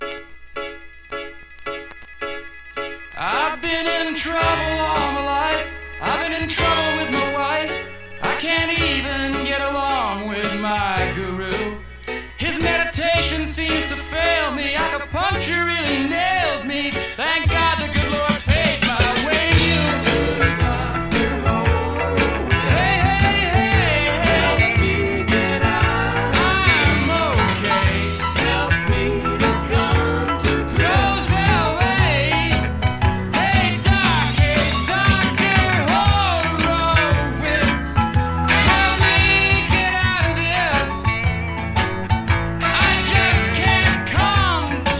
Rock, Pop, Country Rock　USA　12inchレコード　33rpm　Stereo